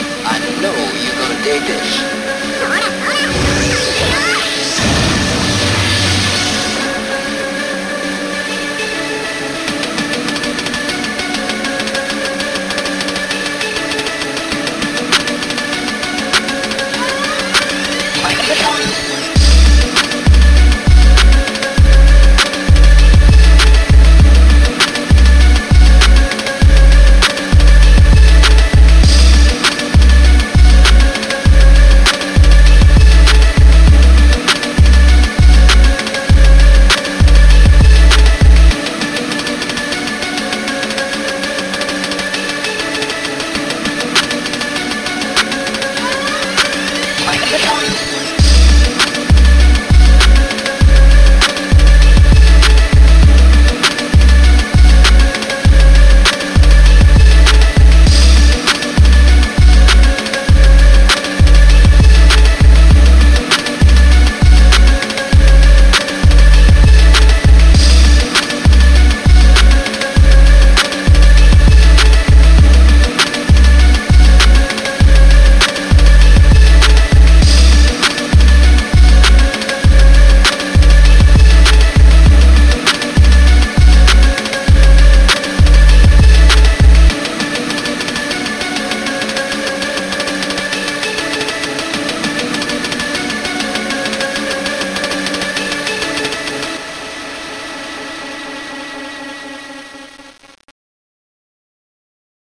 too heavily sampled